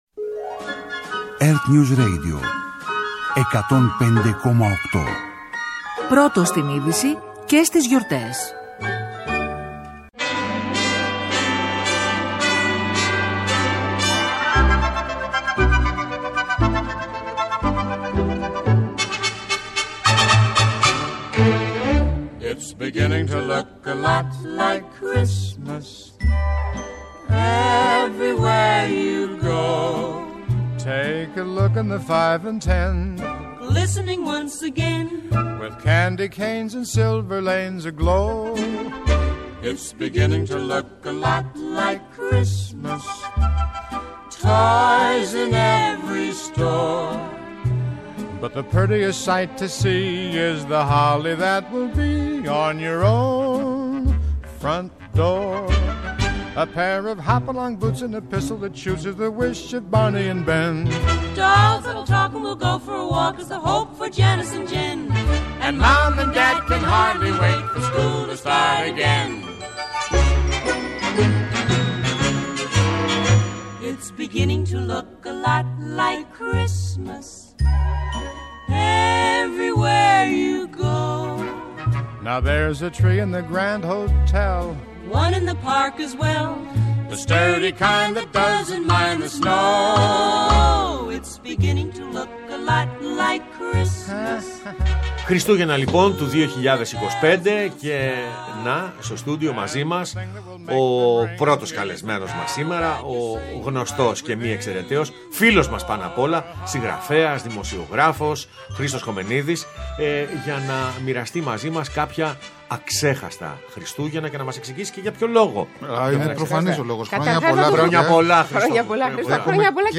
Άνθρωποι της τέχνης, του θεάματος, γνωστοί δημοσιογράφοι και πρόσωπα της επικαιρότητας αφηγούνται